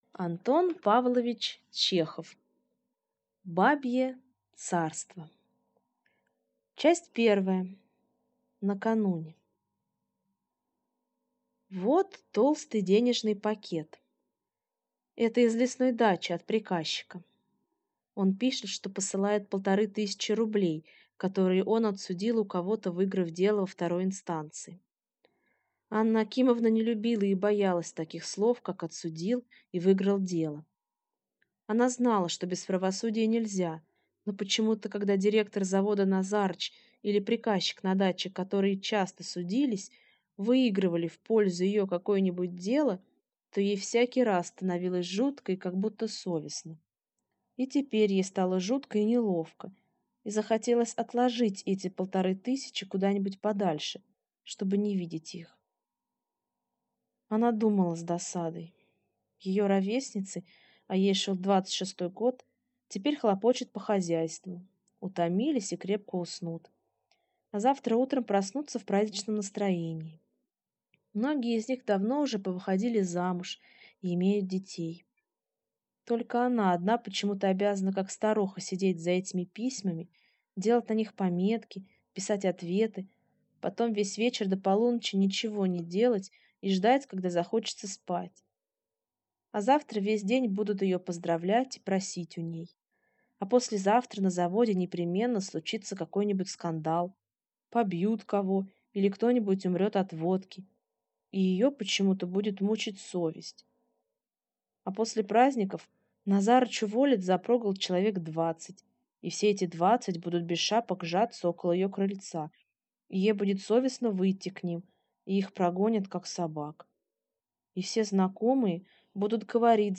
Аудиокнига Бабье царство | Библиотека аудиокниг